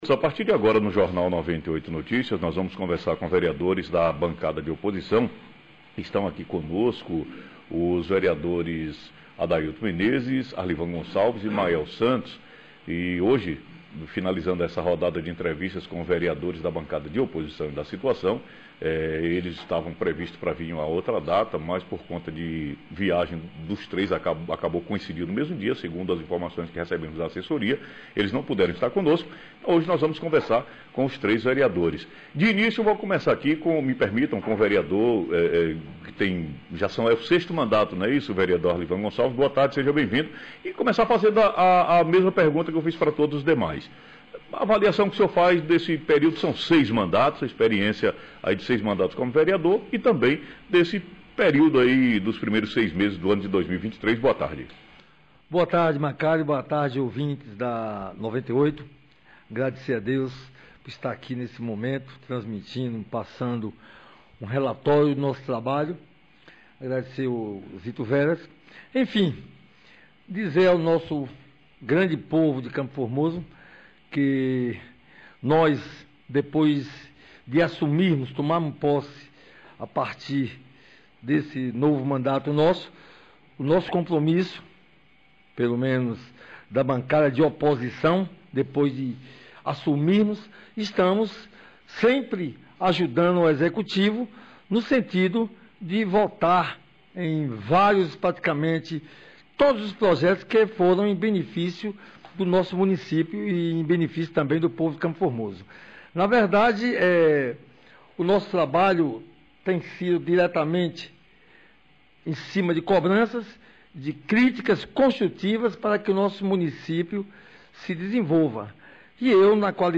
Entrevista com os vereadores do município de CFormoso, da bancada de oposição